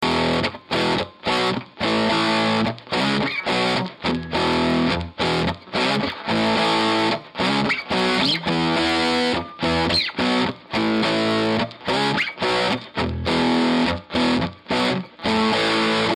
I recorded it using a Lambda pre-box and Shure SM57.
The guitar is a faded les paul double cut with old(ish) P-90's. It is a recent acquisition and sounds very nice.
The guitar was plugged into the bright channel input 2.....the volume was pretty much cranked with the treble on 8, mids on 3-4 and bass on 0.
I Have a Marshall bluesbreaker RI and it does not have the bold strident tone or chunky bottom that the Trinity has.
The SM57 was right at the grillcloth at the Alnico Tone Tubby.